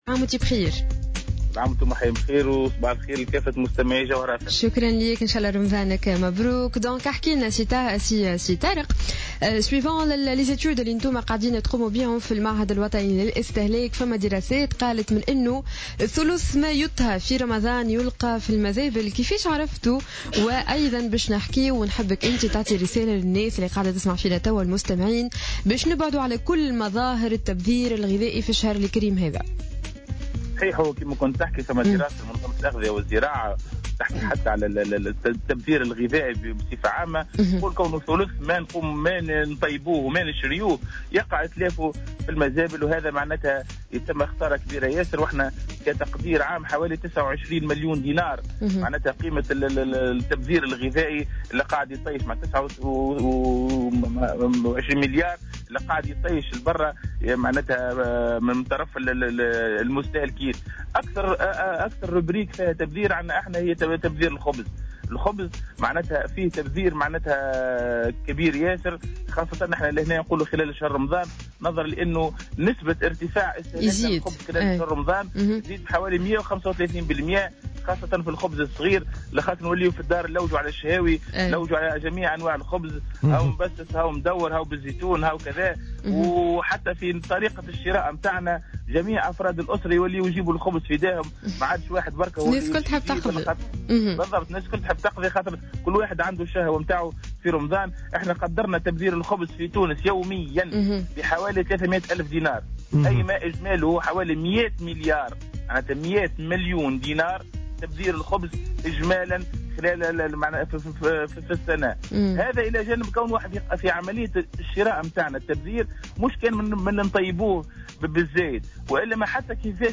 Le directeur général de l’institut national de la consommation (INC) Tarek Ben Jazia, a indiqué ce vendredi 19 juin 2015 dans une intervention sur les ondes de Jawhara FM, que le tiers des produits alimentaires achetés chaque jour, par un tunisien finissent à la poubelle.